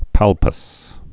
(pălpəs)